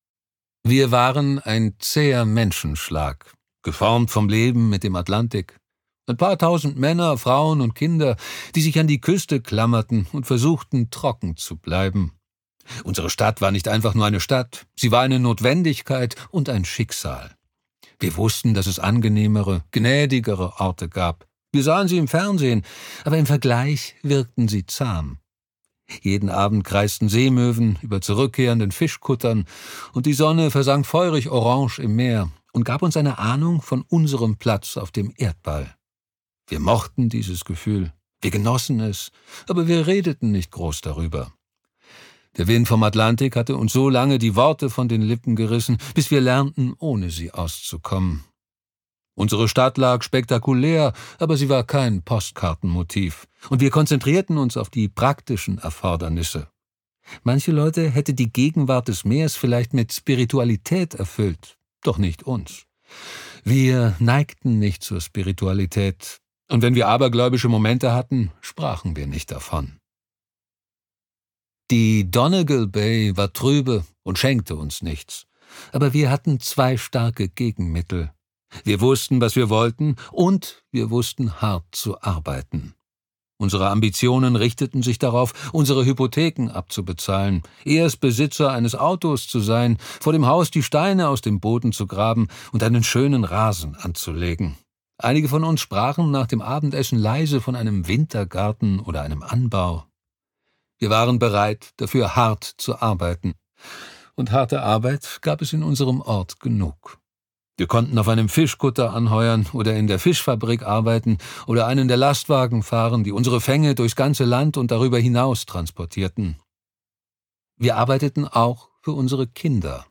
Gekürzte Lesung